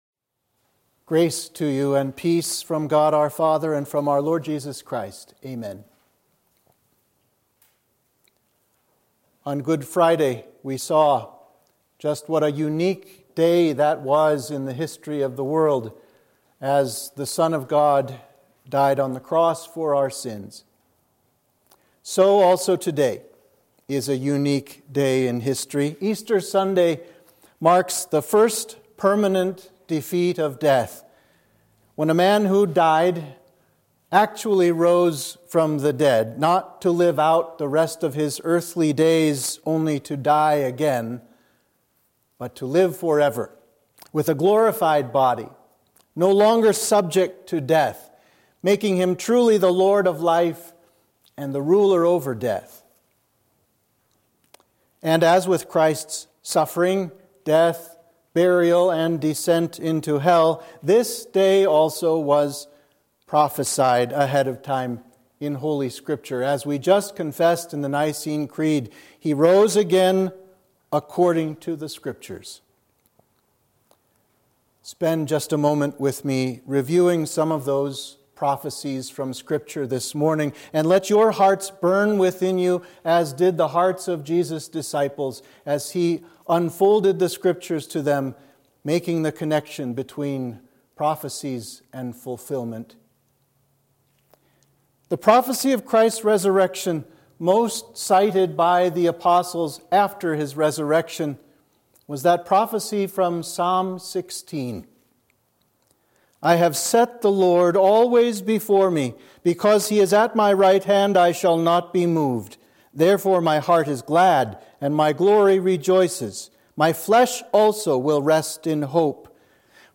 Sermon for Easter Sunday